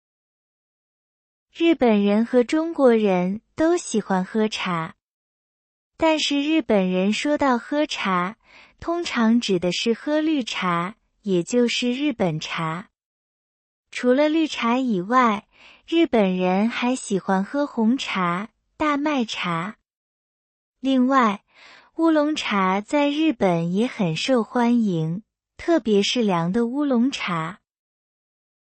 中国語の音声
クリアーで聞きやすい音源に出来ています。
中国の音声をWordの読み上げ機能から取り出す方法に関しては、別の記事でご紹介しています。